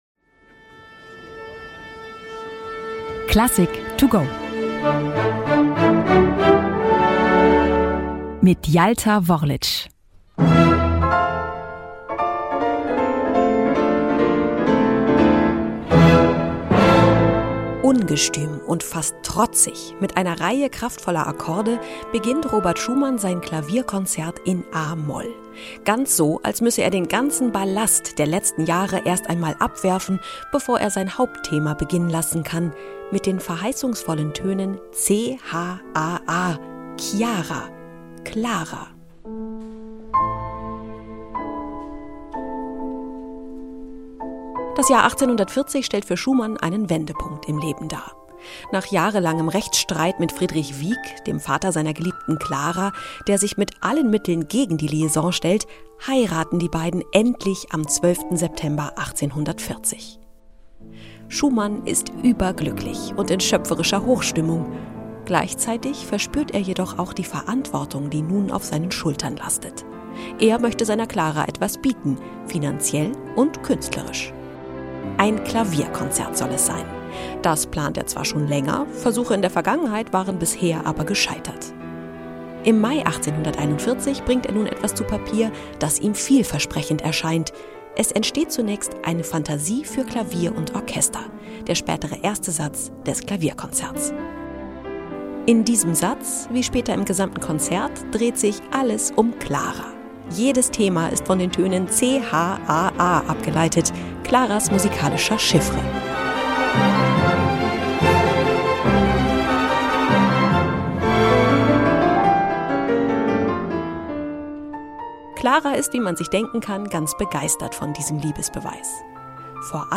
der kurzen Werkeinführung für unterwegs.